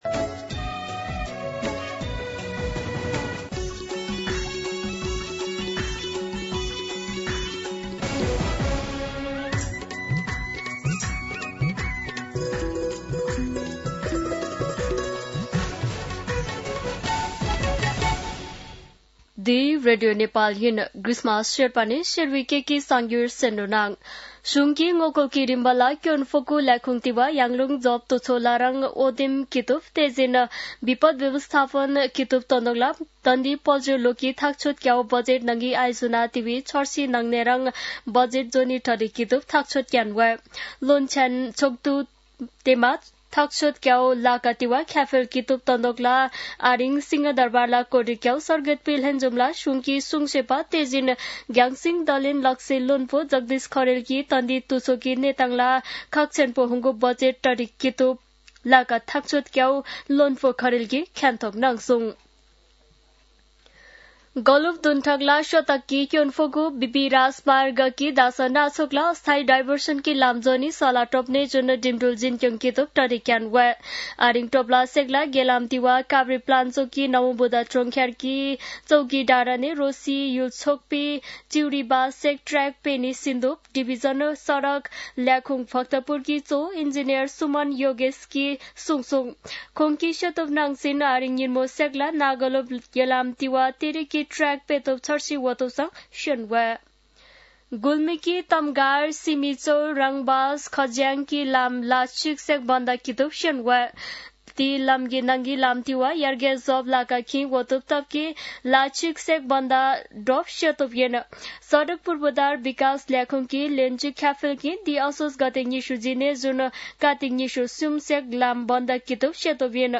शेर्पा भाषाको समाचार : २४ असोज , २०८२